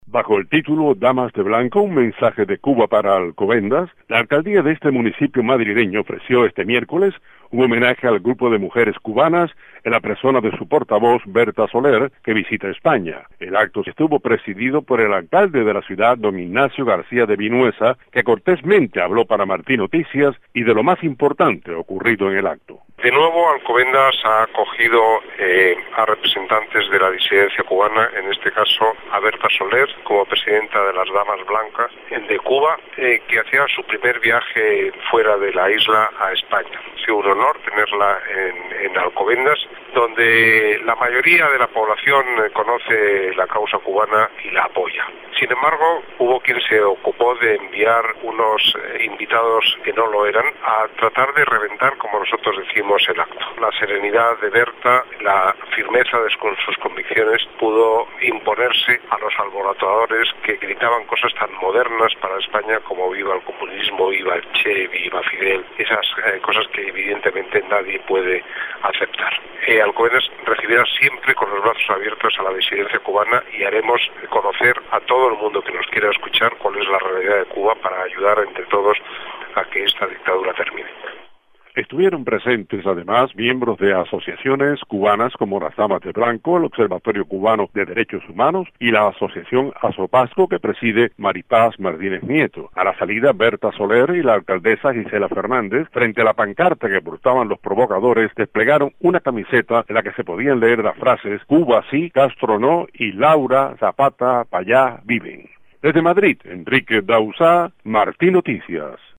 El alcalde de la ciudad española de Alcobendas, Ignacio García de Vinuesa, habla a martinoticias sobre el acto realizado este miércoles, con la líder del grupo disidente cubano "Damas de Blanco", Berta Soler, de visita en esa localidad, al norte de Madrid.